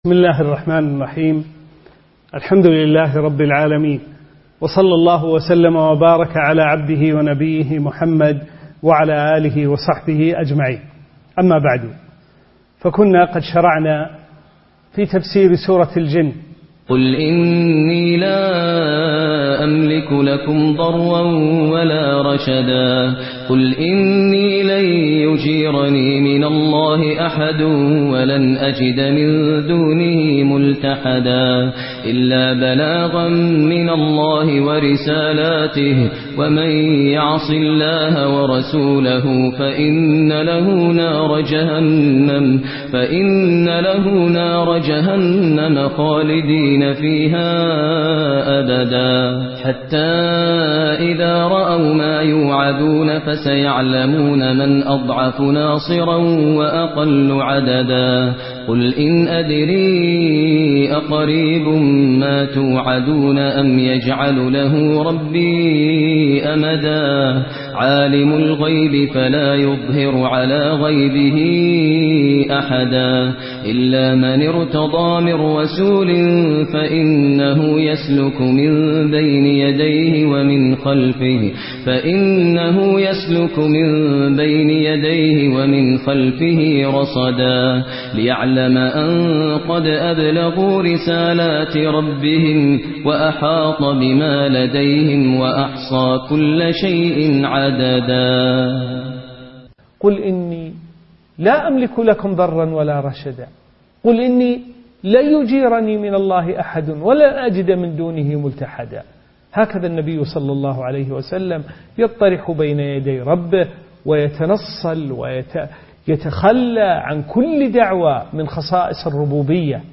الدرس الخامس والعشرون : سورة الجن: من قوله: (قُلْ إِنِّي لَا أَمْلِكُ لَكُمْ ضَرًّا وَلَا رَشَدًا)، إلى آخر السورة.